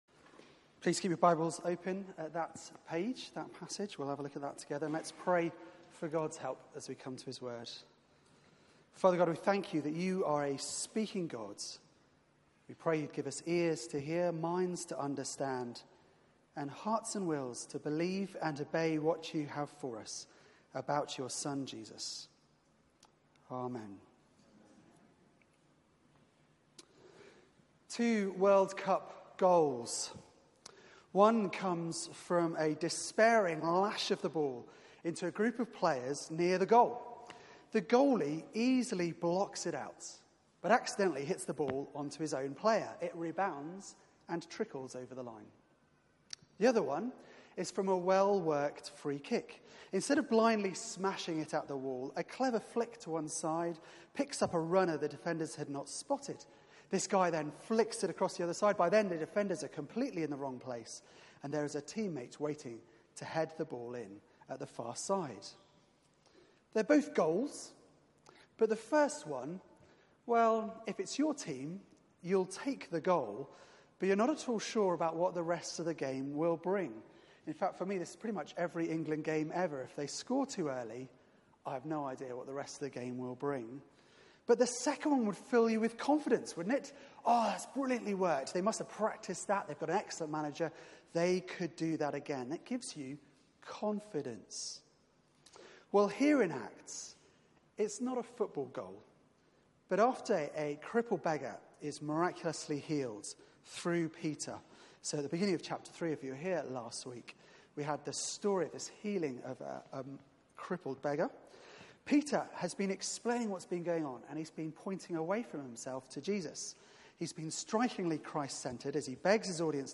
Media for 6:30pm Service on Sun 22nd Jul 2018 18:30 Speaker
Theme: Two ways to live Sermon